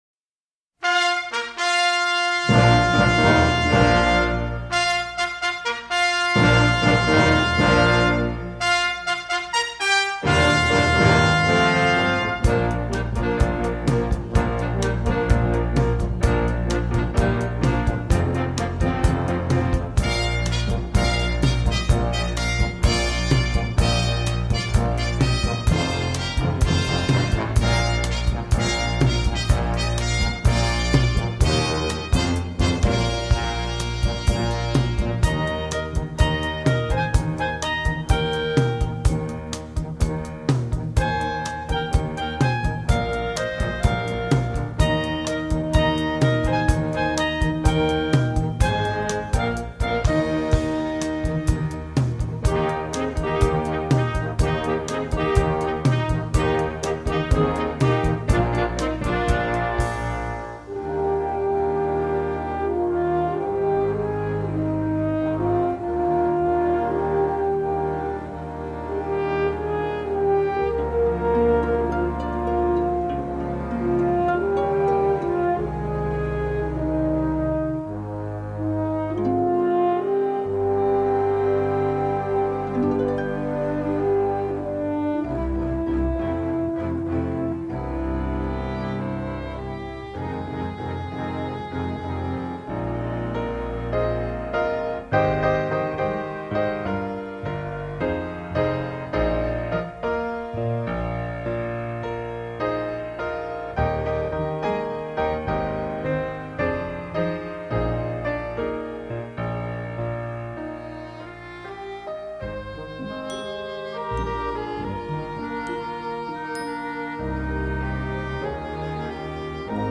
Du piano, du saxo, de la tendresse…
le score est romantique et enlevé